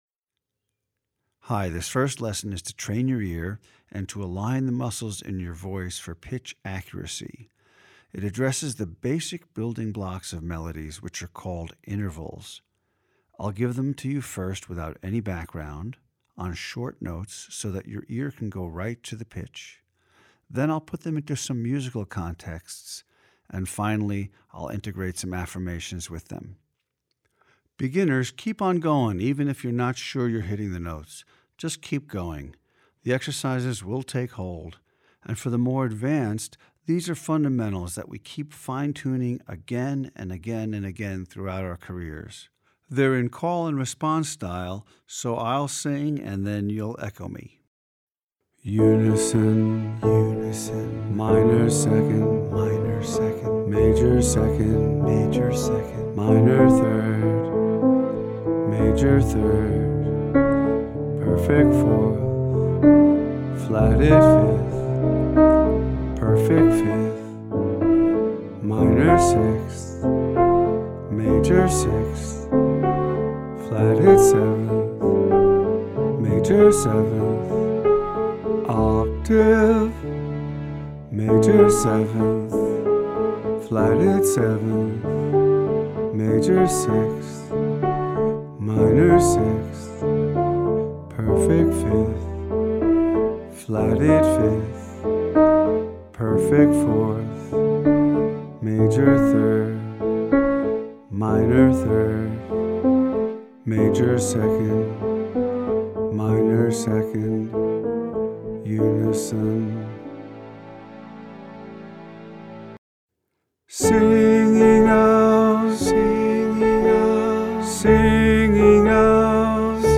The Chromatic Scale
the-chromatic-scale